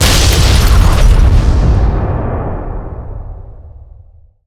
fx_explosion_nuke_small_3d.wav